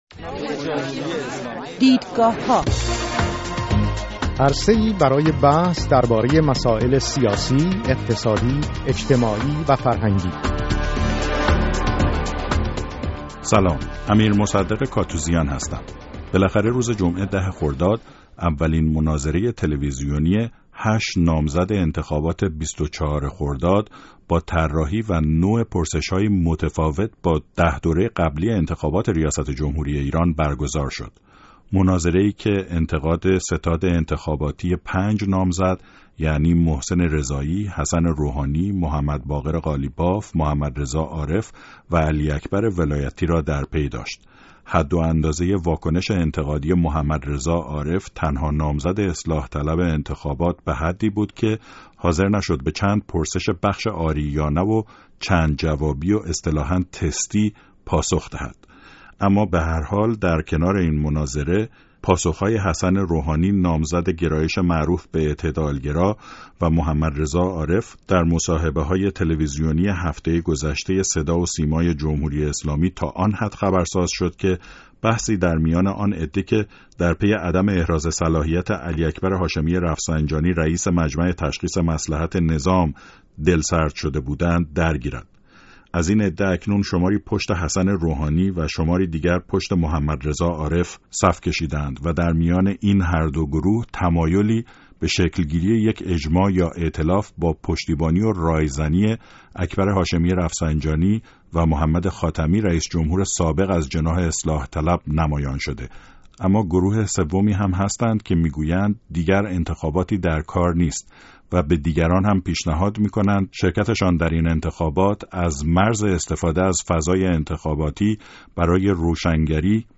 دیدگاهها بحث و مناظره